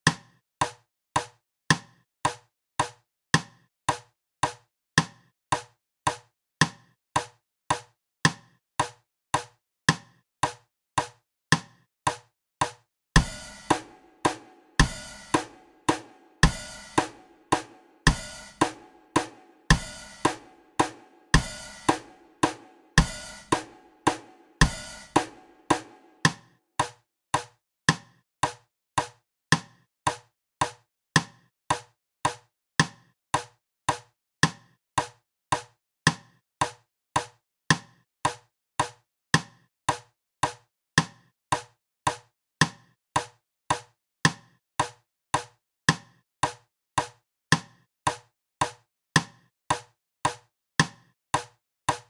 Nedan finns ljudfiler och tillhörande bilder för 4-takt och 3-takt.
Trupp A 3-takt (mp4)